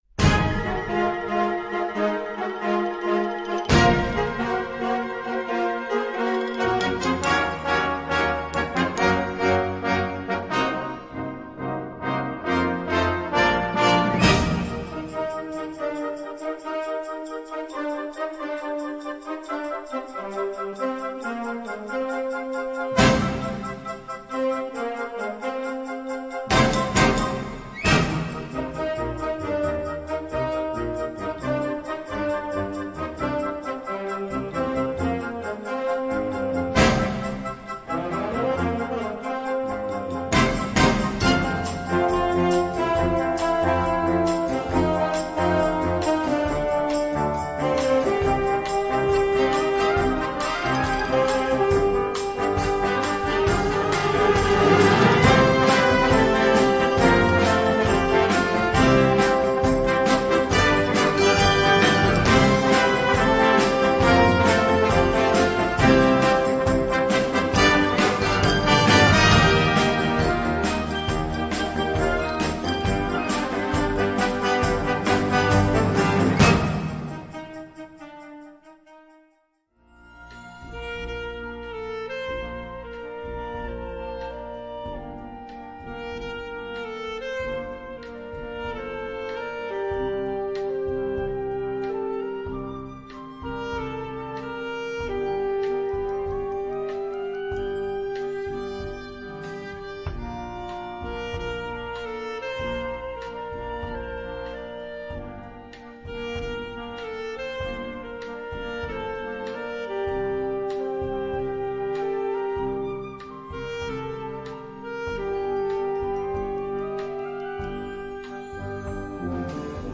Gattung: Moderne Blasmusik
Besetzung: Blasorchester